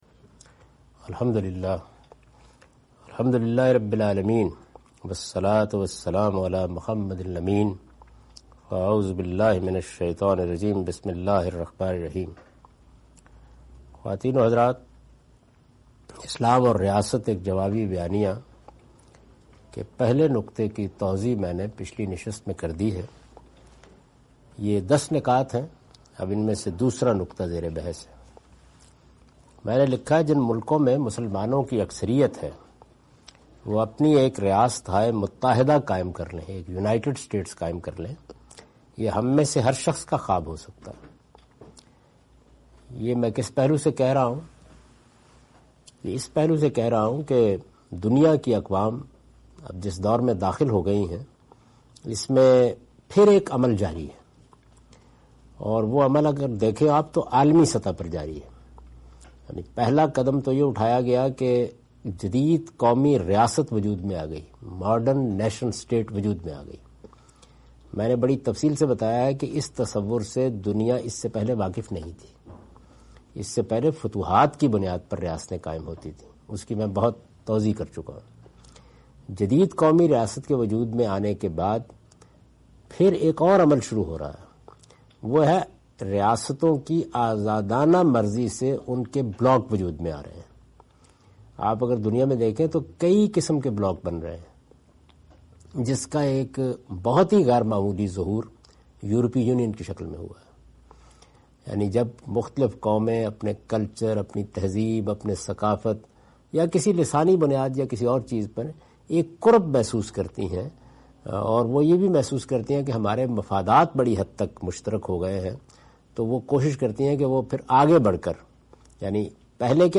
In this video, Javed Ahmad Ghamidi presents the "Counter Narrative" of Islam and discusses the "Resolution goals". This lecture was recorded on 11th June 2015 in Dallas (USA)